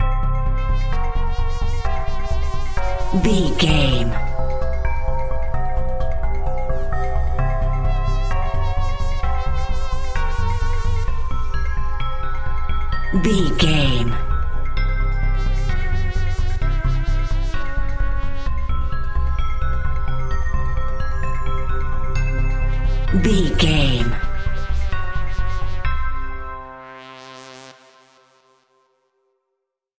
Augmented
eerie
hypnotic
medium tempo
ominous
percussion
drum machine